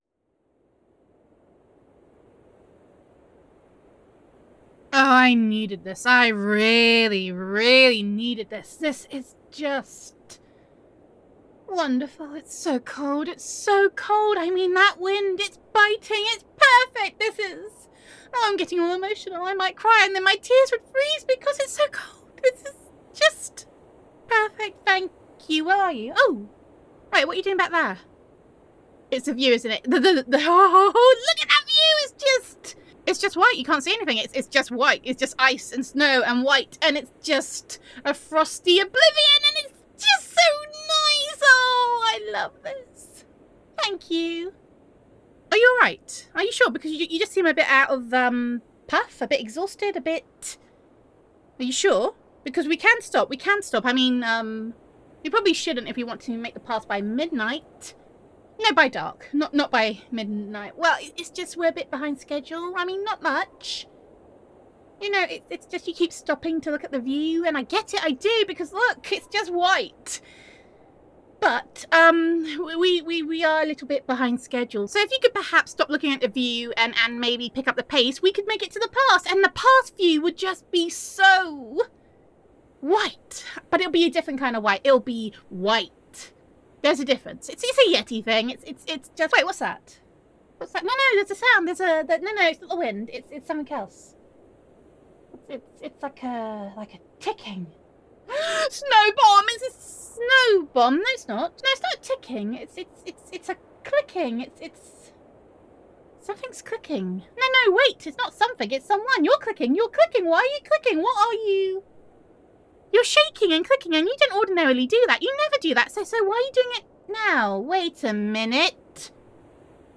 [F4A]
[Yeti Girl Roleplay]